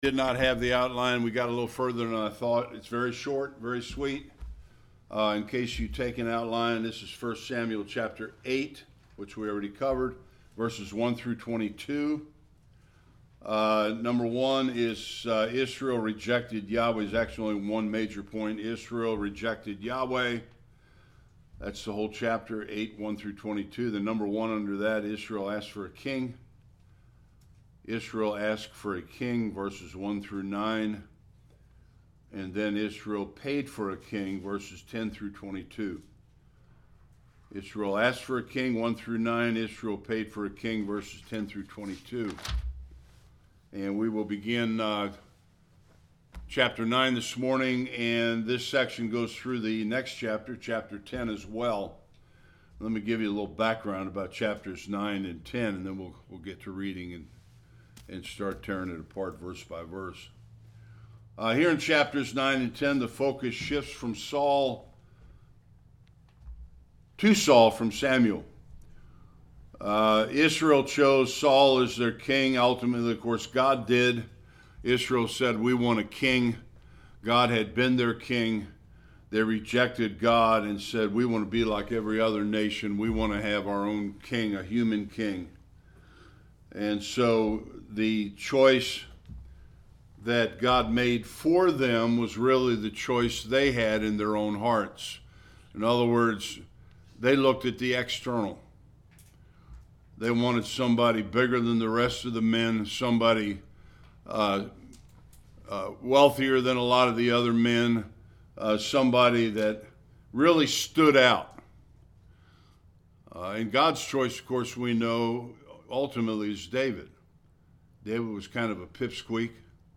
1-25 Service Type: Sunday School Saul is anointed Israel’s king.